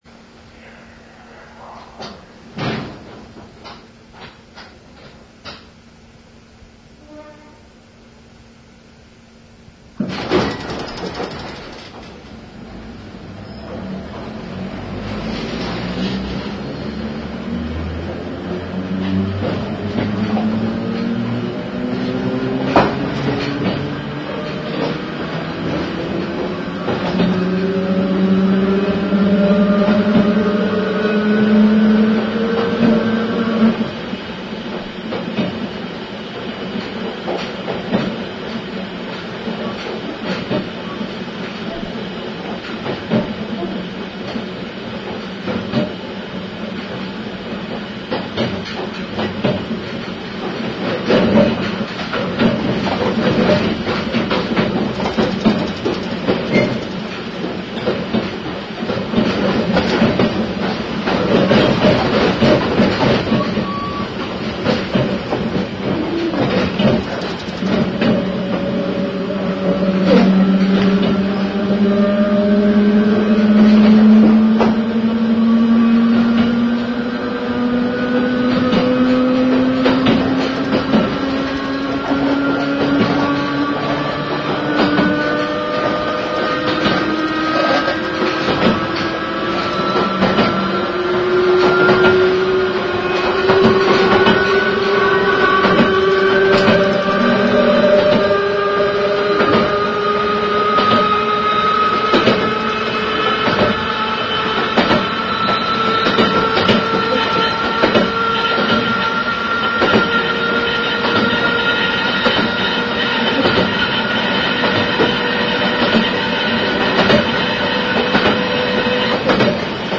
元テープは古く、また録音技術も悪いため、音質は悪いかも知れません。
８３、２　岡谷・下諏訪間　飯田線車両の夜間、中央東線区間運転にて、岡谷構内をシリースで渡った後、一気に加速する